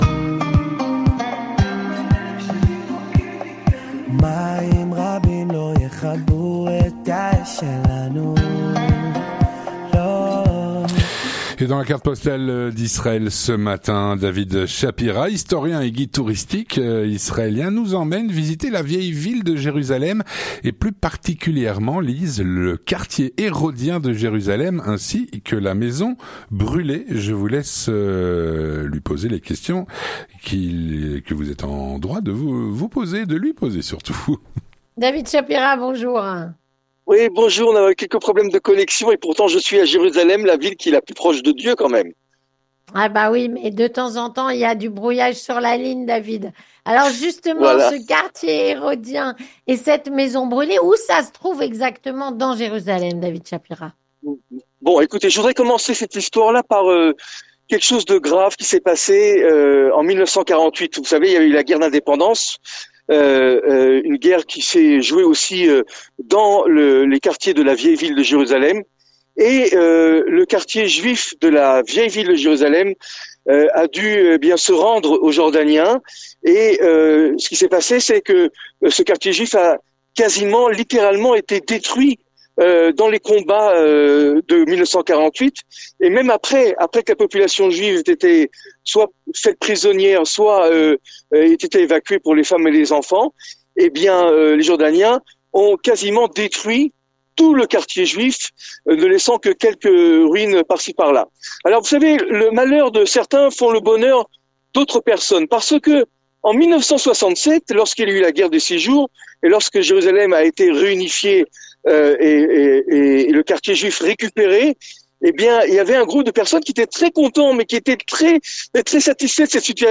3. Les Chroniques de la Matinale